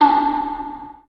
Mafia - Perc 2.wav